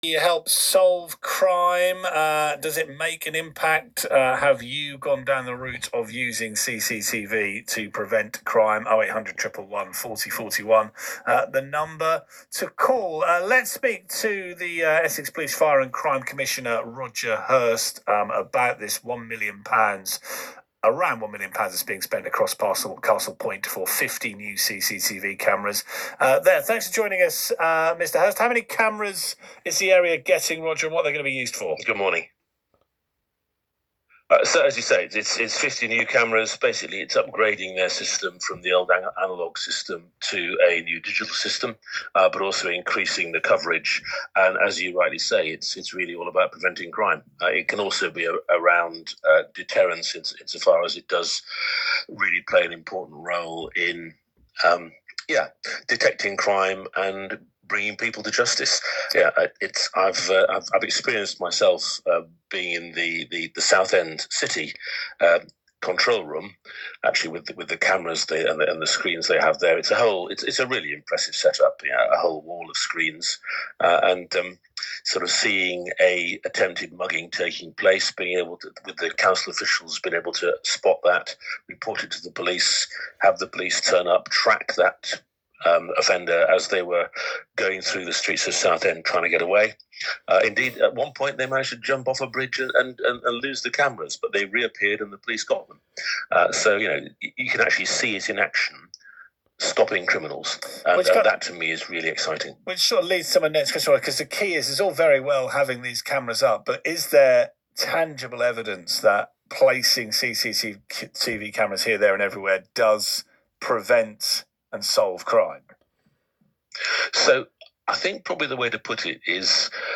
Roger Hirst, Police, Fire and Crime Commissioner speaking to BBC Essex.